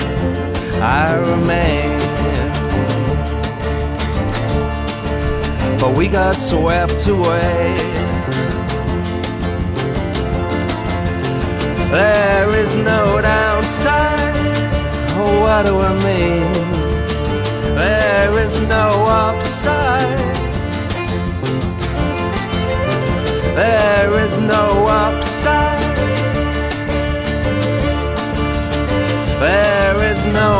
enregistré et mixé en Suède